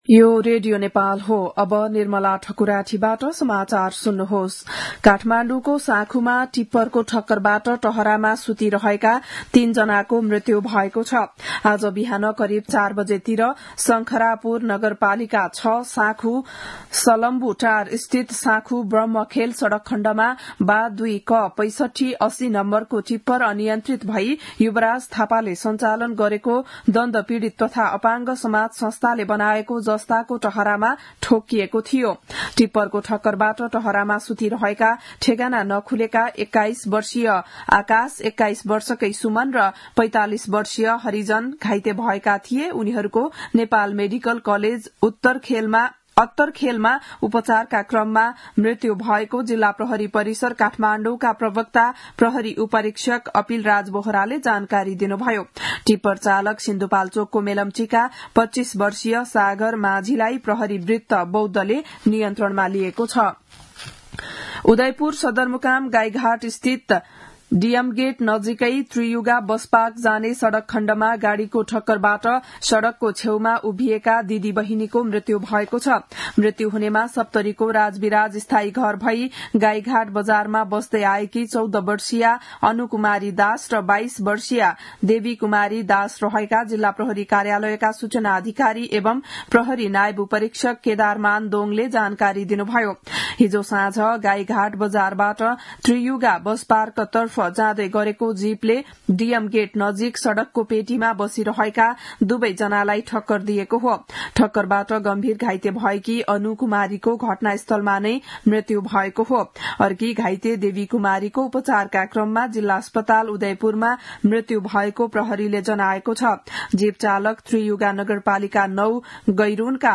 बिहान ११ बजेको नेपाली समाचार : २४ फागुन , २०८१
11-am-news-1.mp3